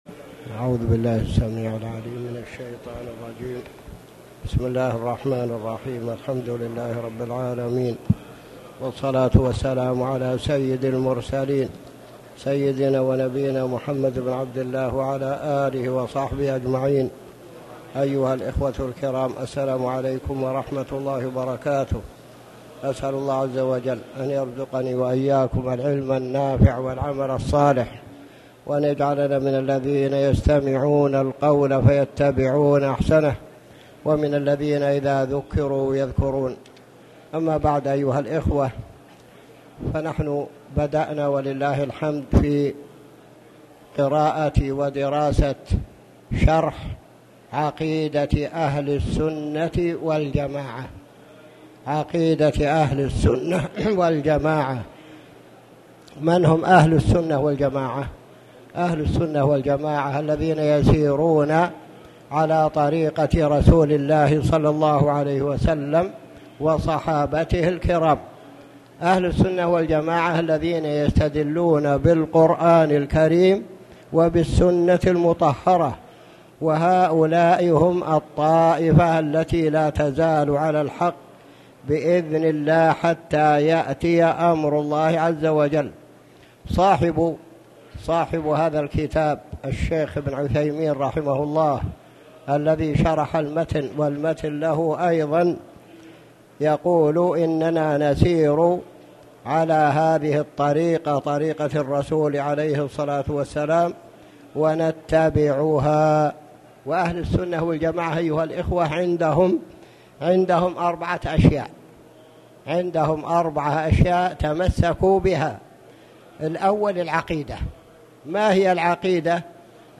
تاريخ النشر ١٠ شعبان ١٤٣٨ هـ المكان: المسجد الحرام الشيخ